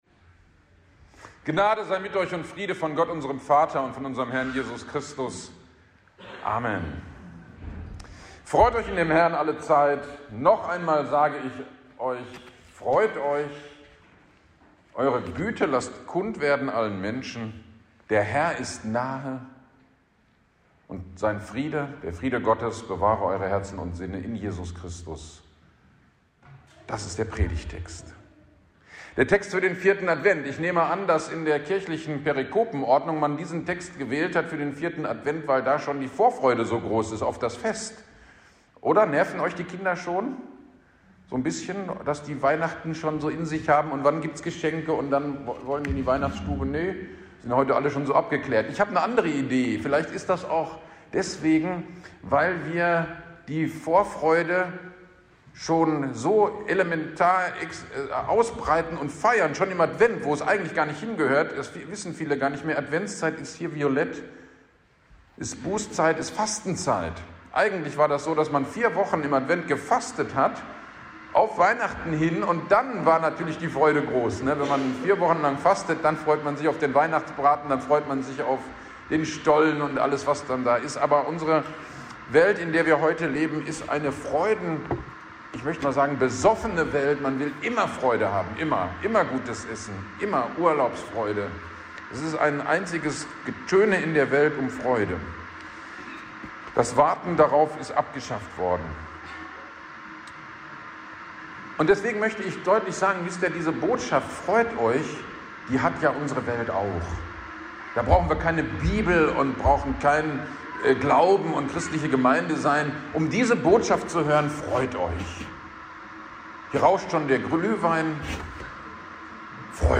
Gottesdienst am 4. Advent 2022 – Predigt zu Philipper 4.4-7